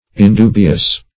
Search Result for " indubious" : The Collaborative International Dictionary of English v.0.48: Indubious \In*du"bi*ous\, a. [L. indubius.